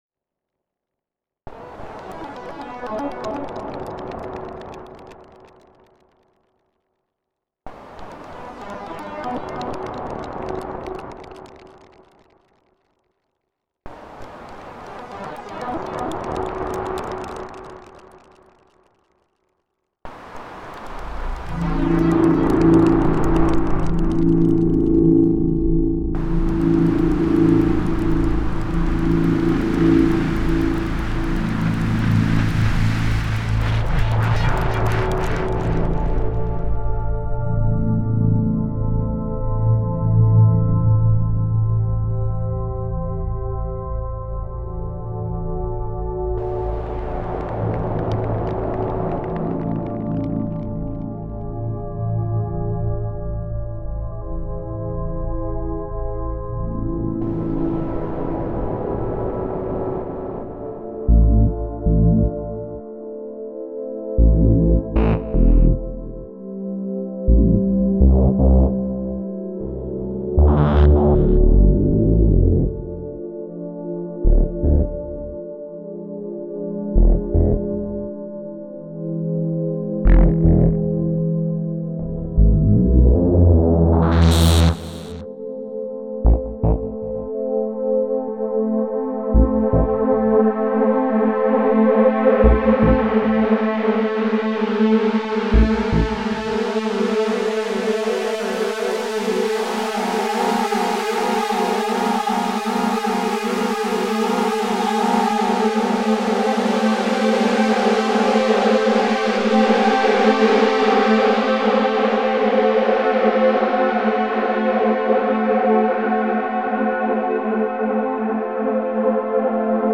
My first foray into sequenced electronic music production with Nuendo.
Absynth was used as the primary synthesizer because of its flexibility; it can work with samples and oscillators influencing each other in nearly unlimited combinations.